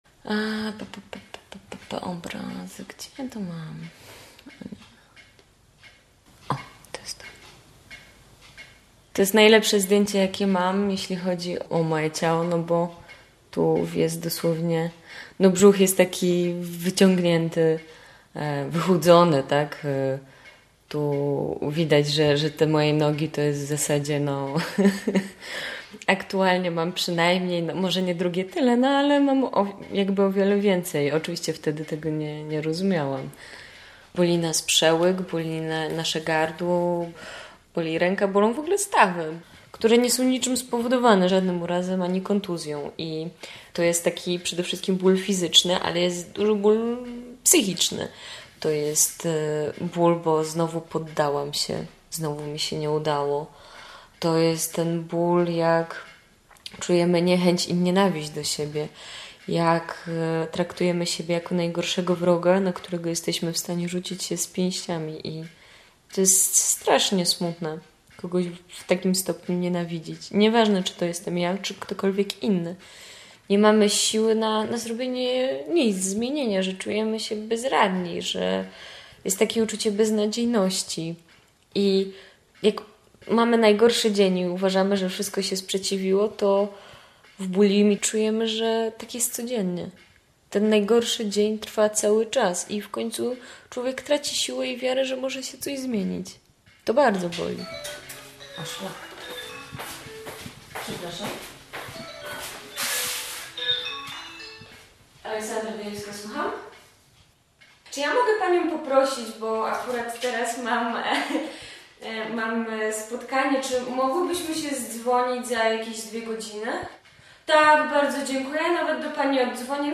Posłuchaj audycji: /audio/dok1/bulimia.mp3 Tagi: choroba dokument psychologia reportaż